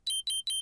PLEASE help me identify this chirp my Teracube is making
It’s 3 sharp chirps.
• I cleared the calendar and alarm apps (Because that sound closely resembles it)